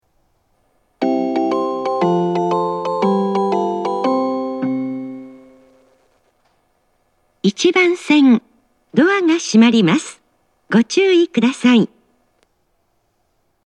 2006年に橋上駅化した際に、放送更新を行ってメロディーの音質が向上しました。
発車メロディー
一度扱えばフルコーラス鳴ります。
小VOSSは音質が大変良いです。